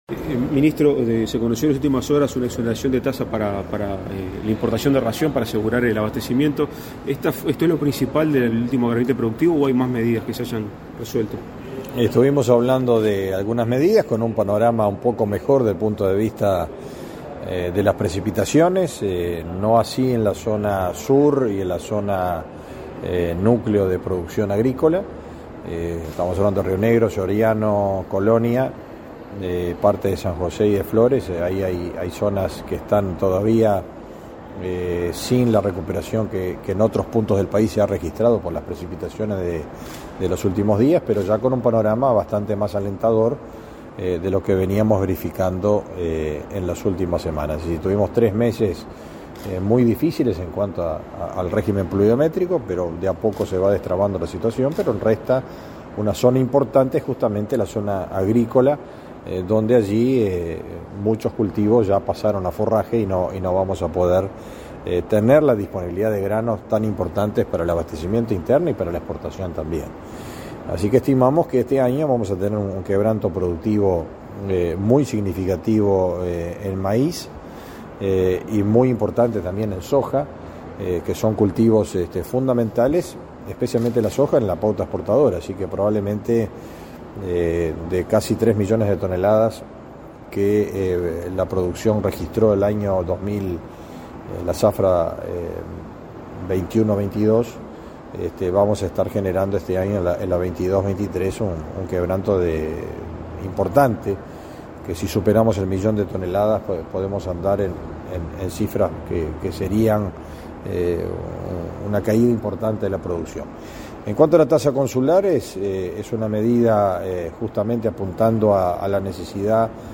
Declaraciones a la prensa del ministro de Ganadería, Agricultura y Pesca, Fernando Mattos
Declaraciones a la prensa del ministro de Ganadería, Agricultura y Pesca, Fernando Mattos 22/03/2023 Compartir Facebook X Copiar enlace WhatsApp LinkedIn Tras participar en la reunión del Gabinete Productivo con el presidente de la República, Luis Lacalle Pou, este 22 de marzo, el ministro Fernando Mattos realizó declaraciones a la prensa.